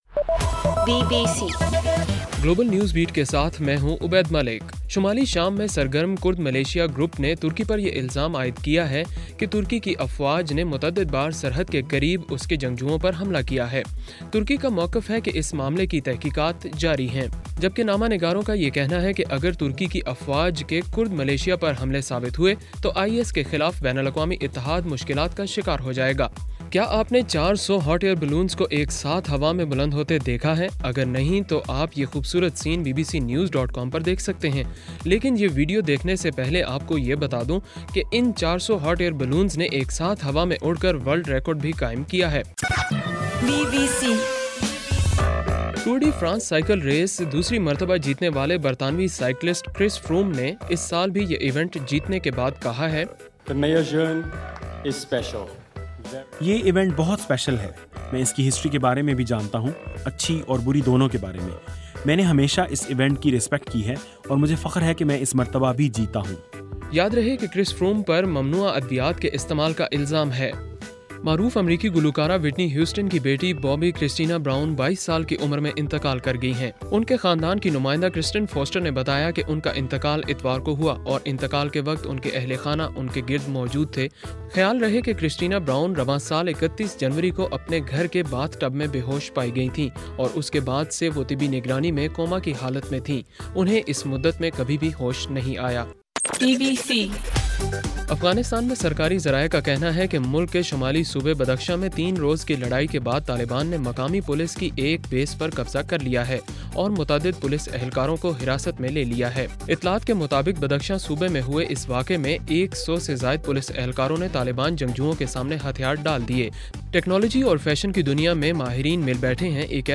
جولائی 27: رات 9 بجے کا گلوبل نیوز بیٹ بُلیٹن